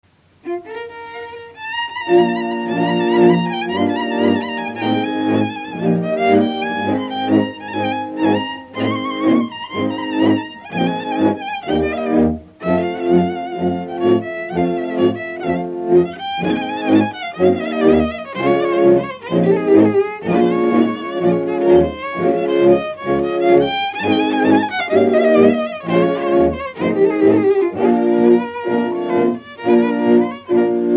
Dallampélda: Hangszeres felvétel
Erdély - Kolozs vm. - Magyarpalatka
hegedű
brácsa
bőgő
Stílus: 1.1. Ereszkedő kvintváltó pentaton dallamok